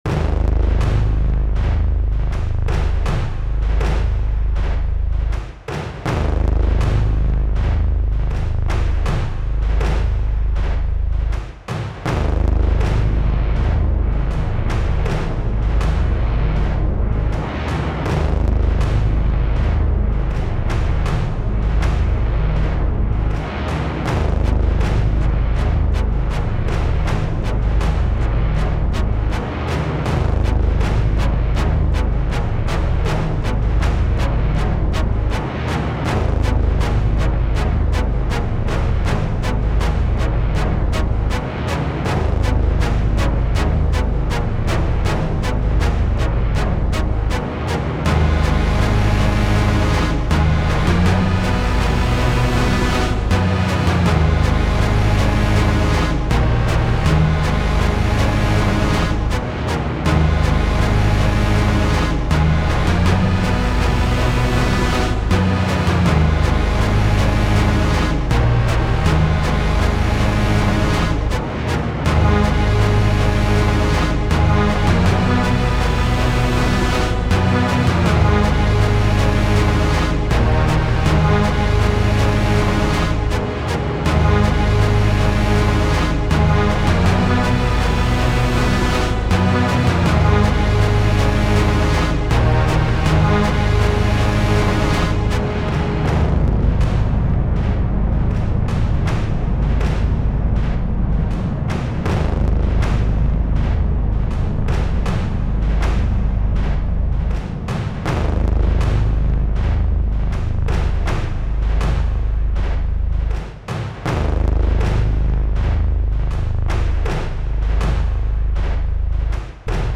タグ: ドキドキ/緊張感 戦闘曲 激しい/怒り コメント: 巨大な存在が登場するシーンをイメージした楽曲。